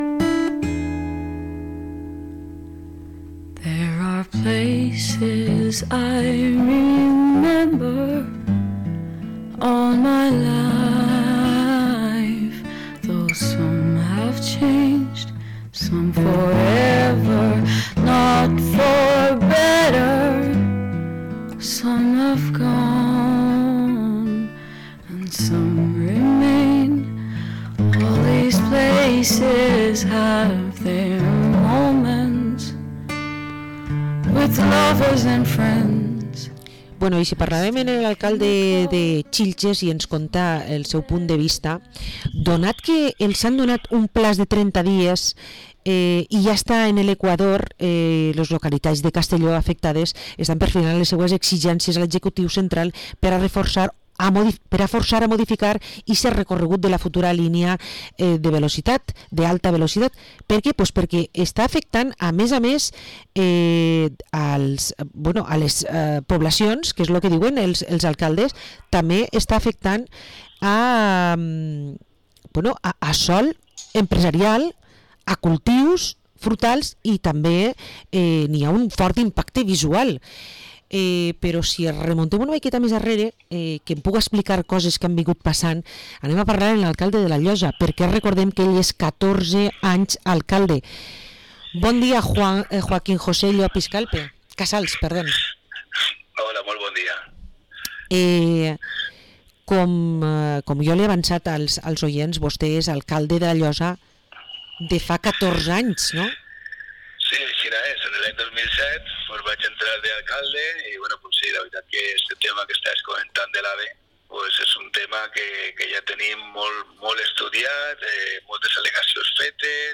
Entrevista al alcalde de la Llosa, Joaquín José Llopis